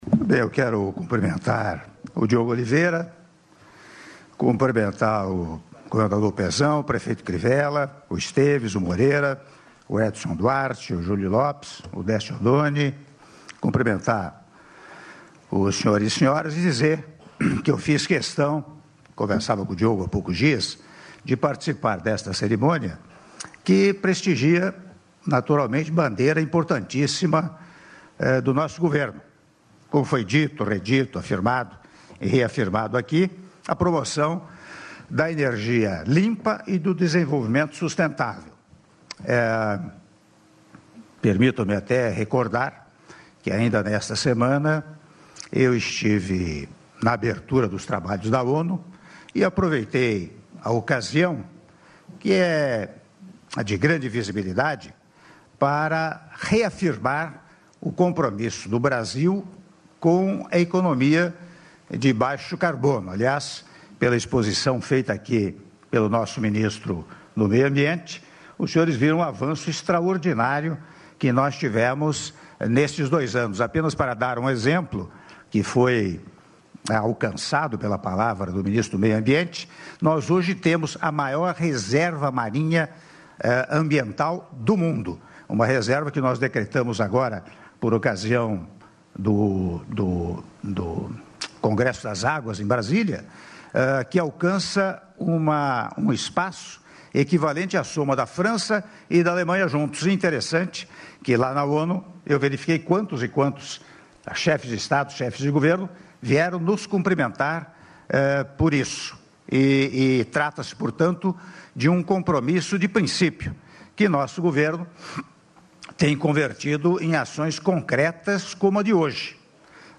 Áudio do discurso do Presidente da República, Michel Temer, durante Cerimônia de Anúncio da Expansão de Investimento em Energias Renováveis - Rio de Janeiro/RJ(05min58s)